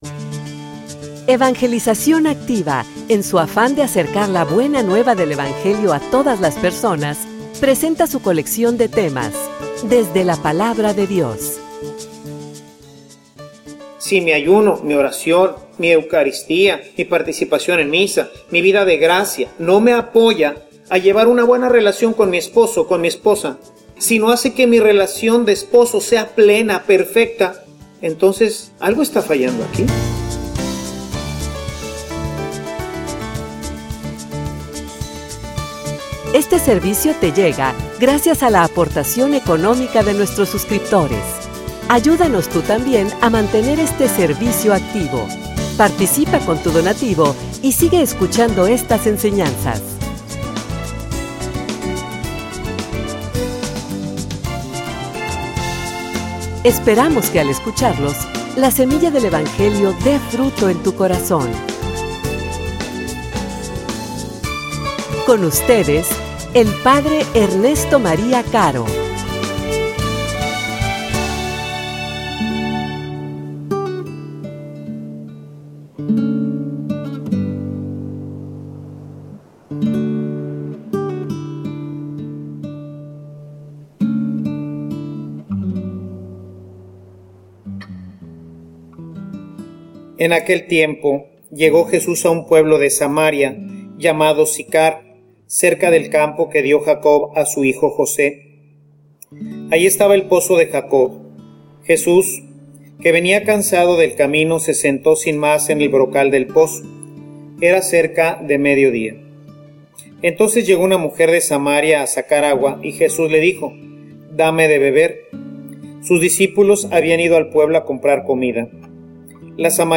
homilia_La_caridad_nos_lleva_a_dar_agua_viva.mp3